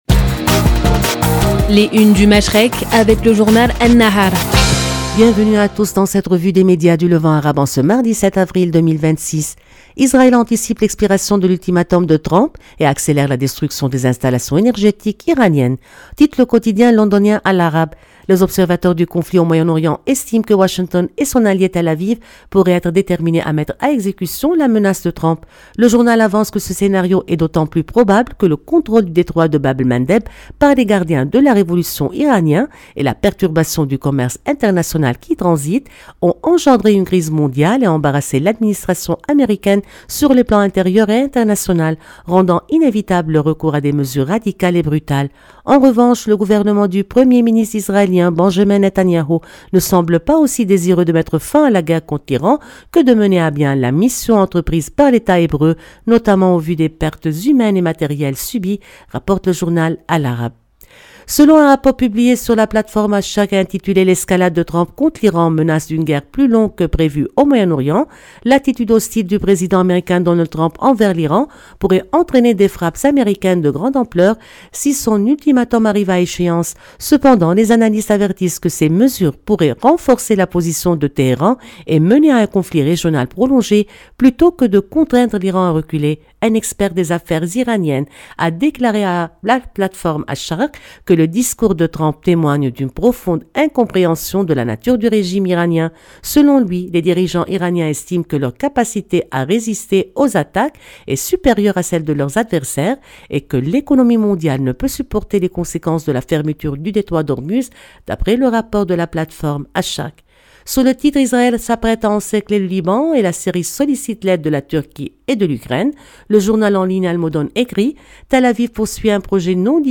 Revue de presse des médias du Moyen-Orient